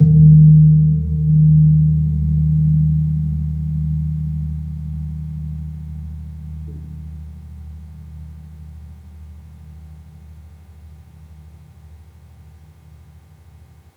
Gong-D#1-p.wav